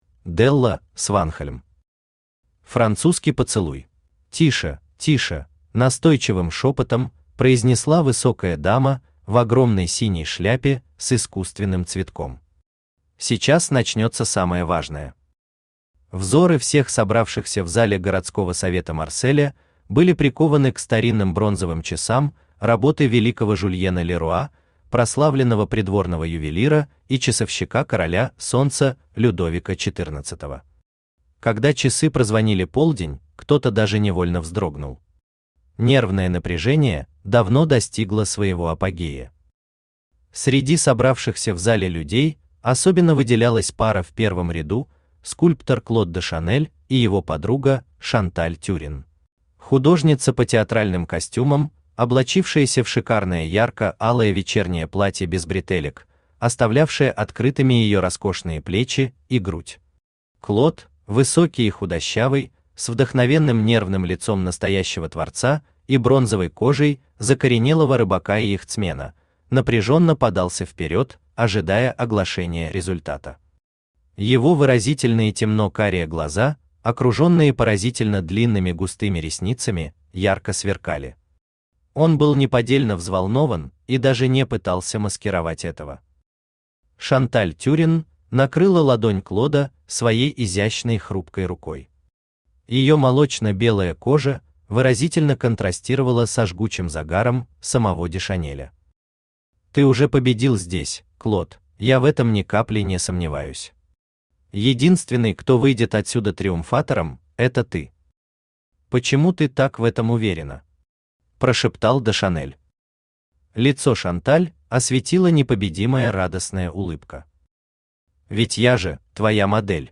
Аудиокнига Французский поцелуй | Библиотека аудиокниг
Aудиокнига Французский поцелуй Автор Делла Сванхольм Читает аудиокнигу Авточтец ЛитРес.